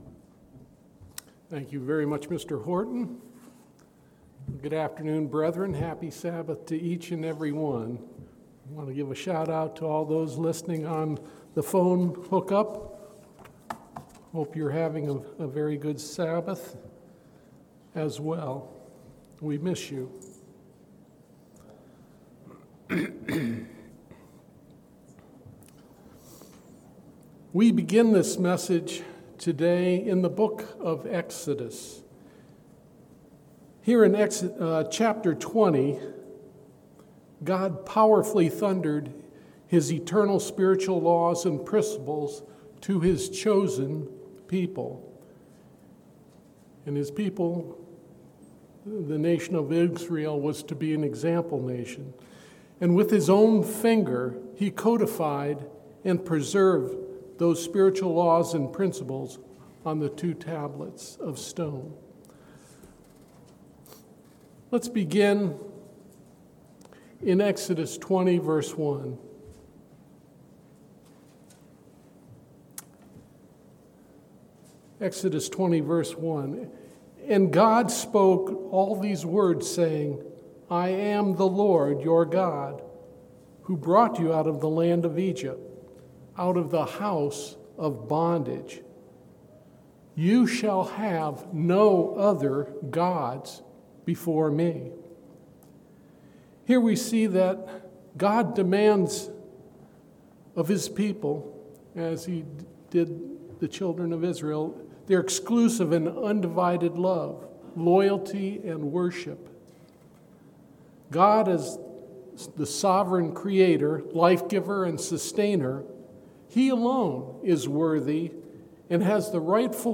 Sermons
Given in Mansfield, OH